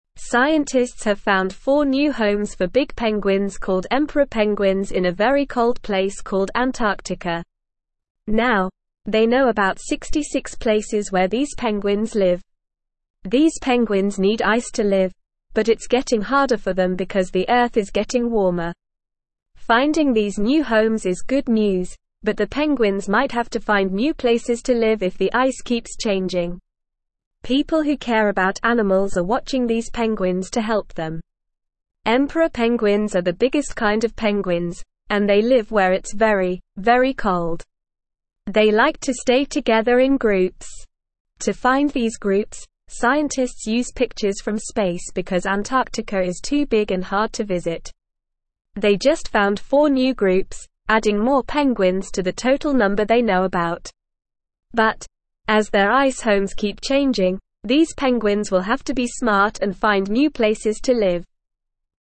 Normal
English-Newsroom-Beginner-NORMAL-Reading-New-Homes-Found-for-Big-Penguins-in-Antarctica.mp3